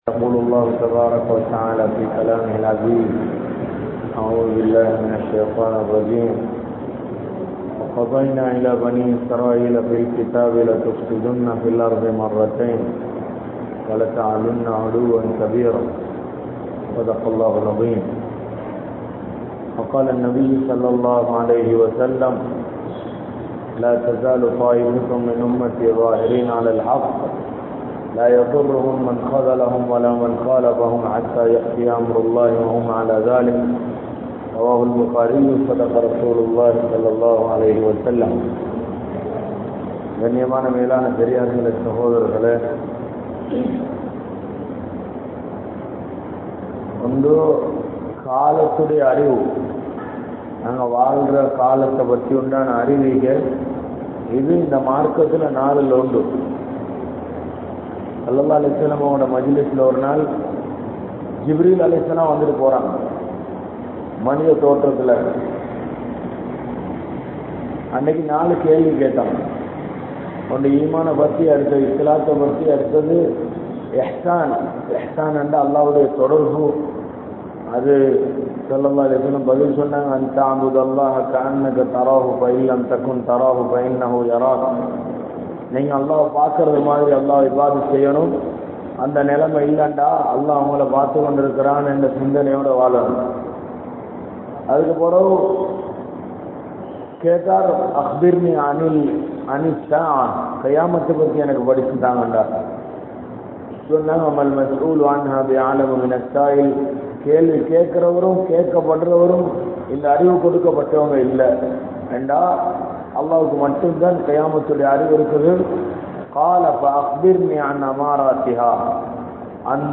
Eamaattrum SCIENCE (ஏமாற்றும் விஞ்ஞானம்) | Audio Bayans | All Ceylon Muslim Youth Community | Addalaichenai